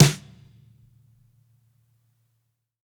GADDISH_SNARE_MED.wav